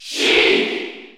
Category: Crowd cheers (SSBU) You cannot overwrite this file.
Sheik_Cheer_French_NTSC_SSBU.ogg.mp3